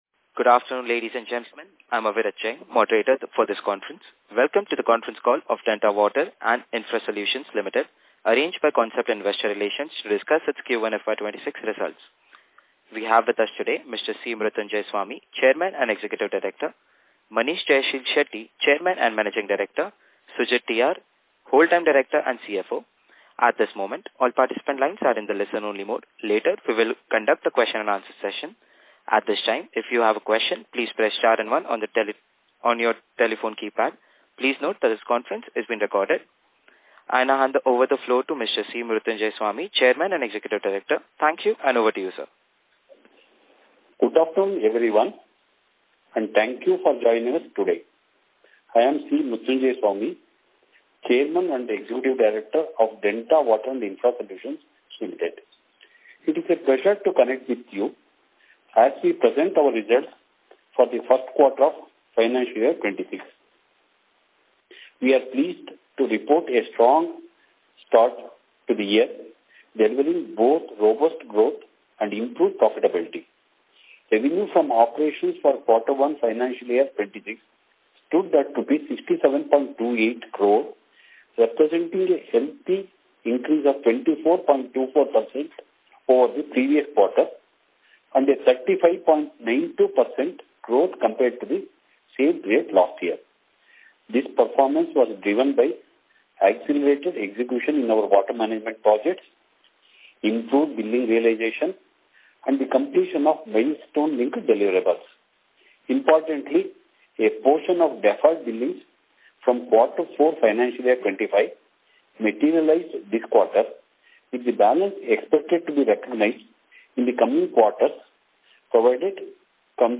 Q1-FY26-Earnings-Con-Call.mp3